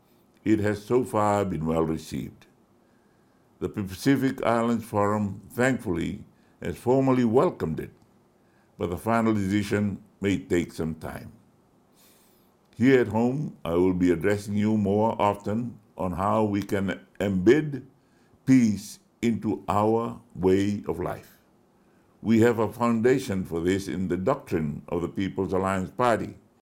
In his Christmas message to the nation, Rabuka made special mention of the health officials, peacekeepers, diplomats, military and police personnel and the members of the Fijian diaspora.